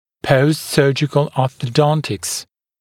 [ˌpəustˈsɜːʤɪkl ˌɔːθə’dɔntɪks][ˌпоустˈсё:джикл ˌо:сэ’донтикс]ортодонтическое лечение после хирургической операции